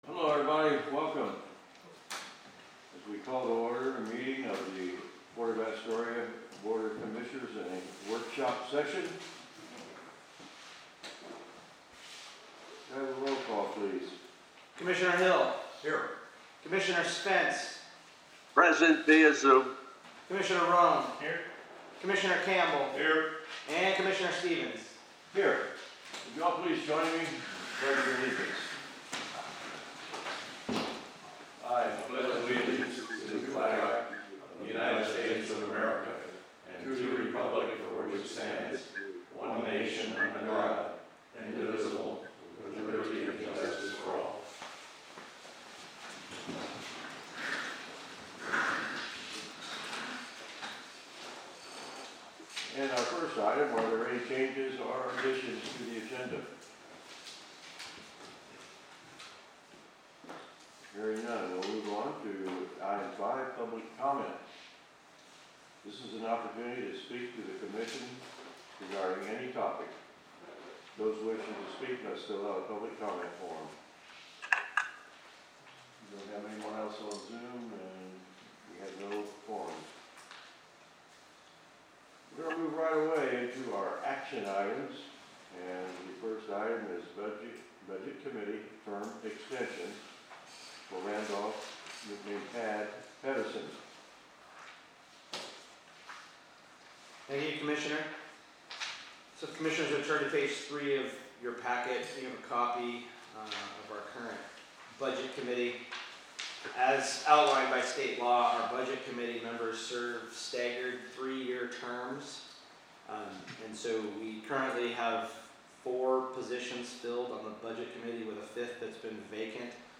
Commission Meeting
422 Gateway Avenue Suite 100, Astoria, OR, at 4 PM